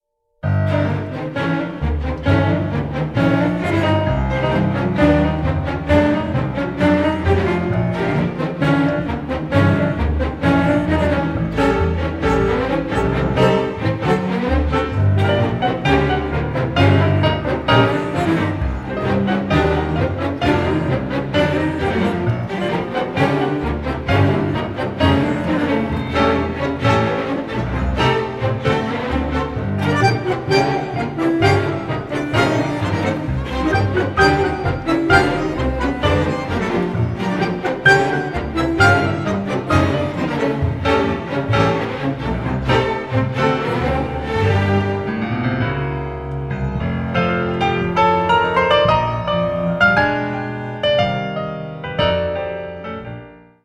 bandoneon and piano